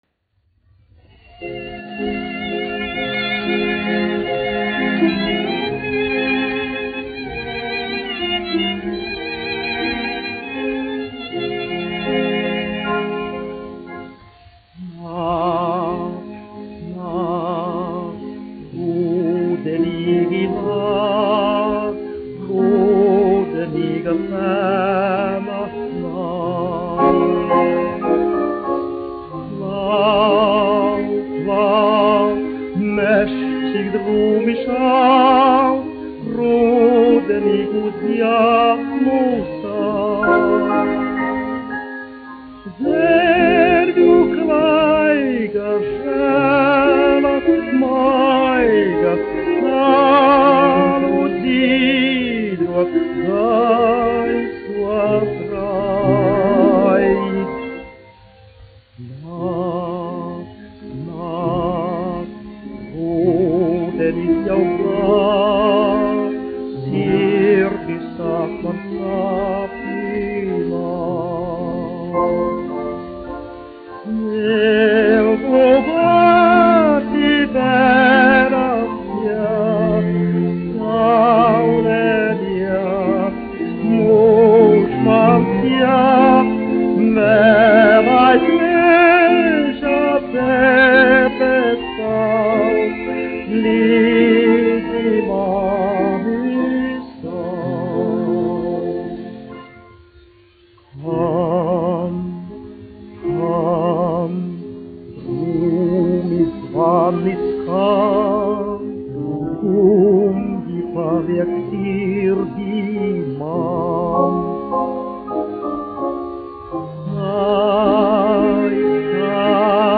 1 skpl. : analogs, 78 apgr/min, mono ; 25 cm
Dziesmas (augsta balss)
Skaņuplate